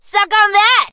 mandy_lead_vo_02.ogg